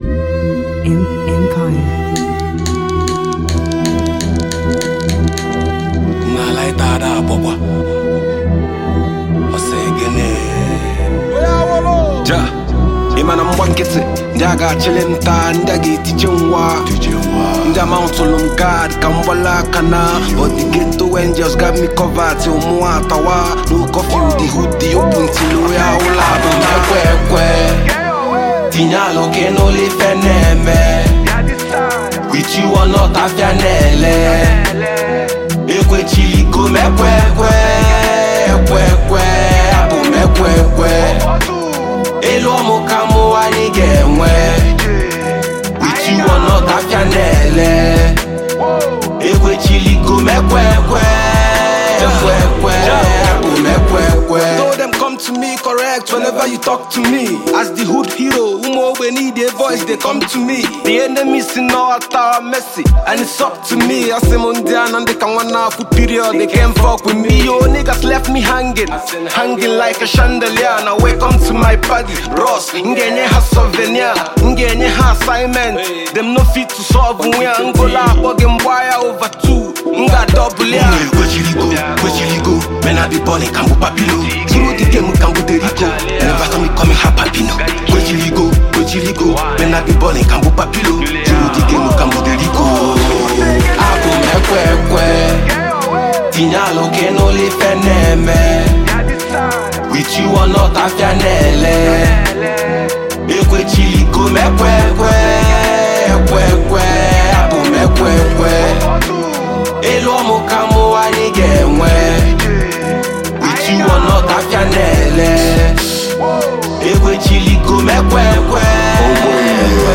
gifted Nigerian singer and lyricist